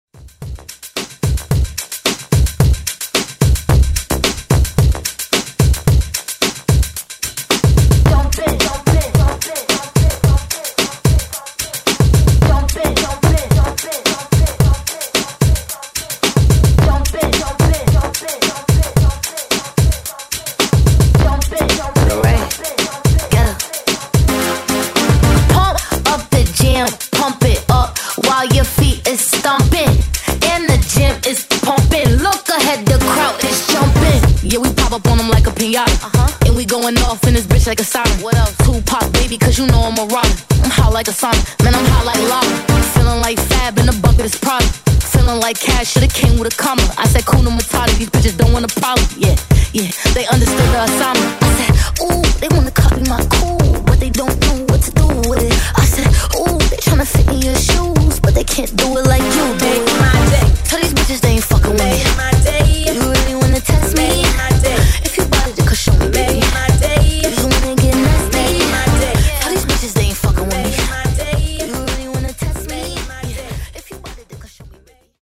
Remix Redrum)Date Added